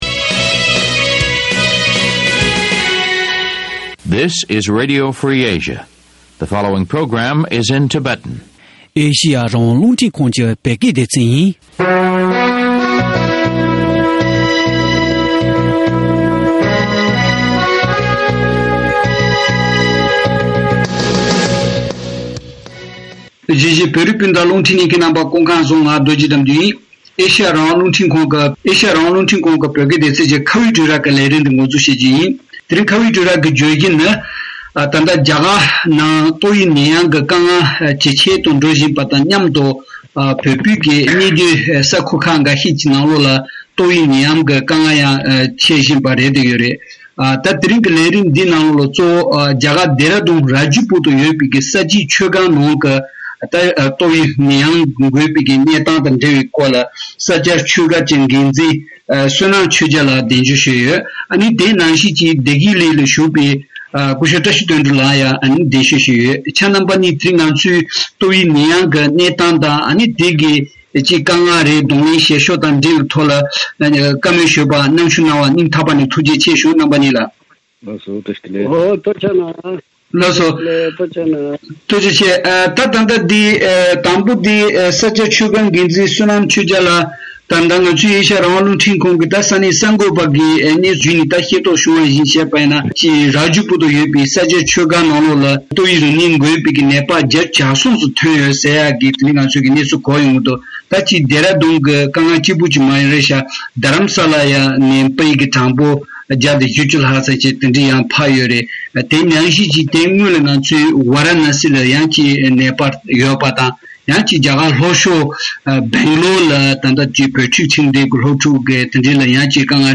བཅའ་འདྲི་བྱེད་པ